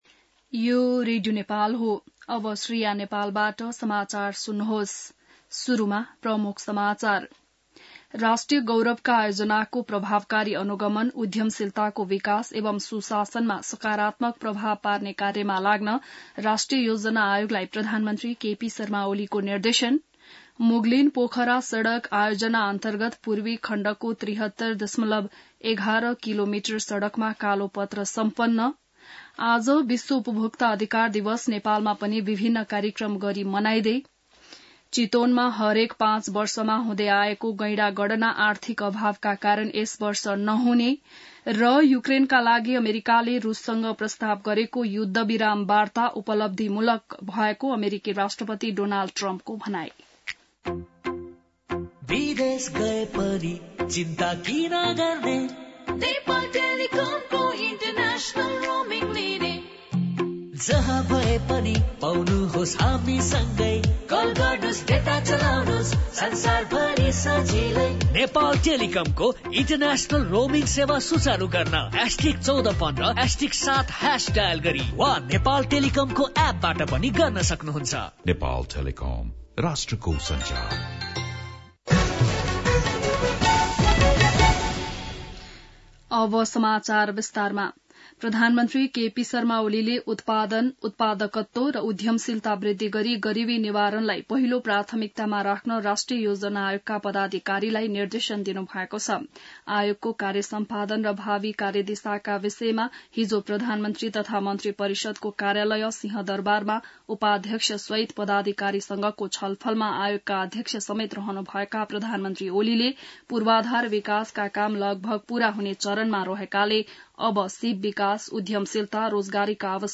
बिहान ७ बजेको नेपाली समाचार : २ चैत , २०८१